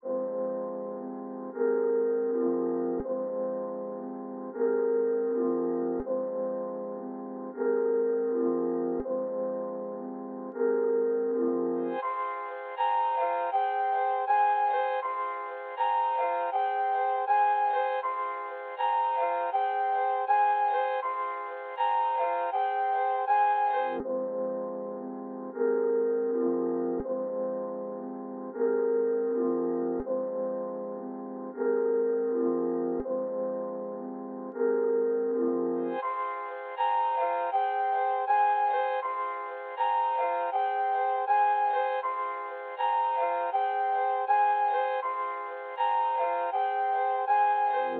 EN - Sap (80 BPM).wav